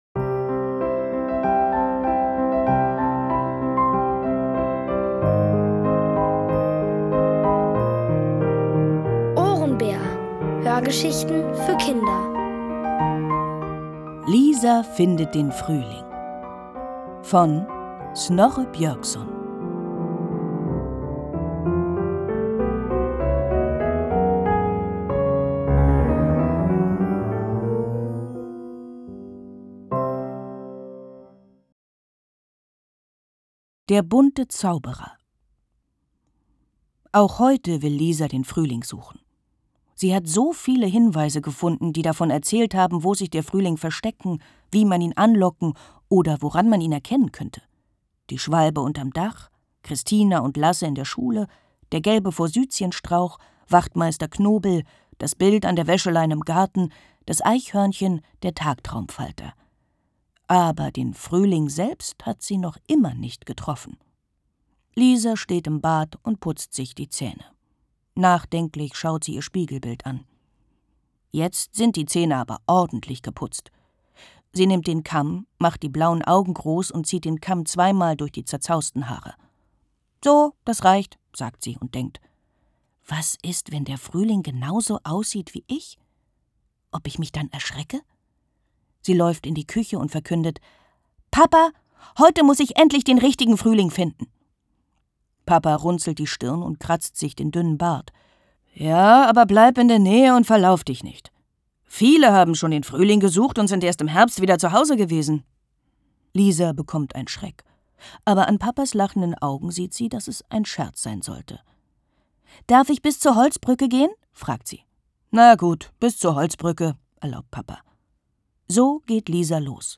OHRENBÄR – das sind täglich Hörgeschichten für Kinder zwischen 4 und 8 Jahren. Von Autoren extra für die Reihe geschrieben und von bekannten Schauspielern gelesen.